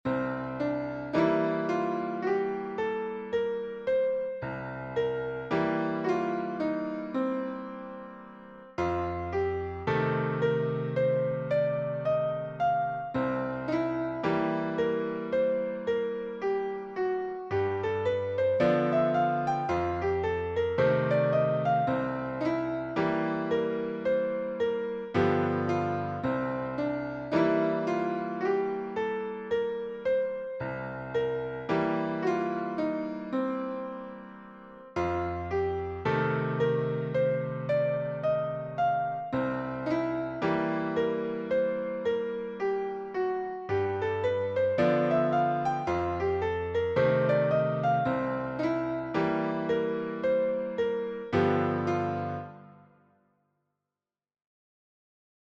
Blues Piano
Grille de Blues
Pour un blues en DO, cela donne :